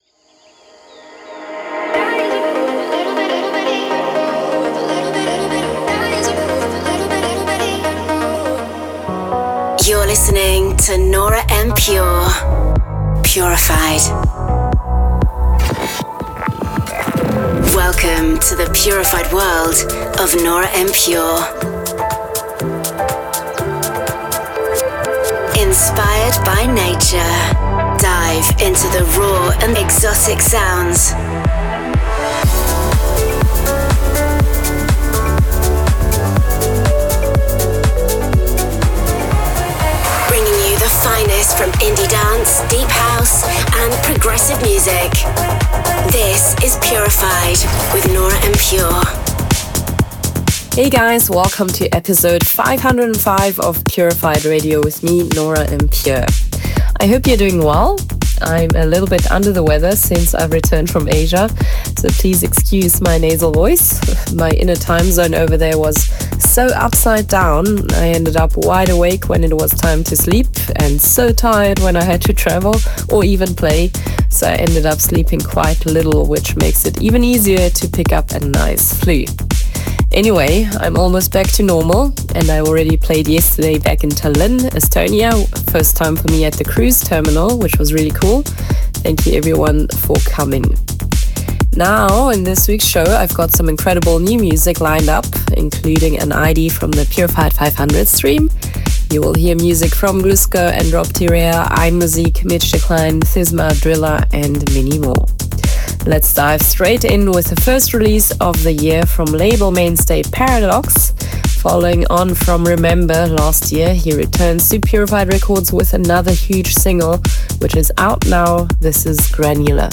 music DJ Mix in MP3 format
Genre: Progressive house; Duration